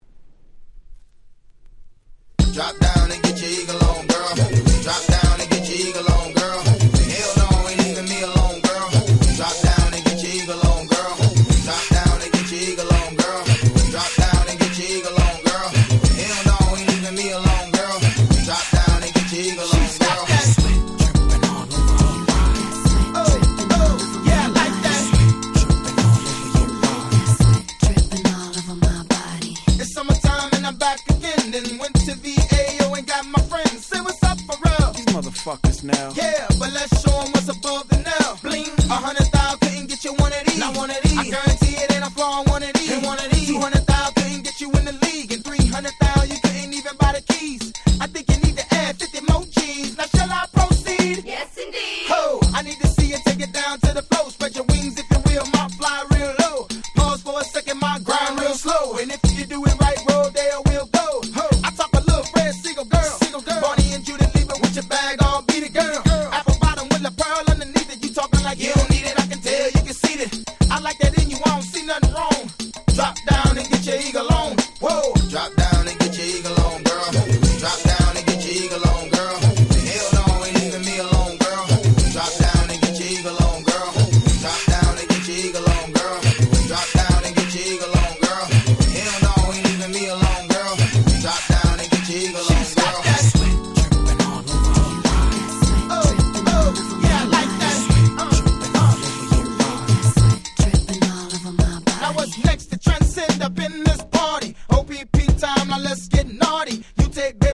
04' Smash Hit Southern Hip Hop !!